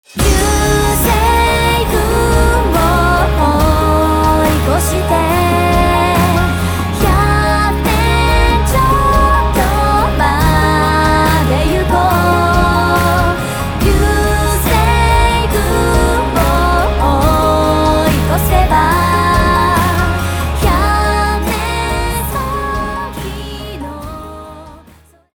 ドラマ - Drama Part -